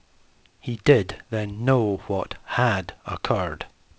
For example we build a databases from a smaller 500 utterance prompt list where every second word was read with emphasis.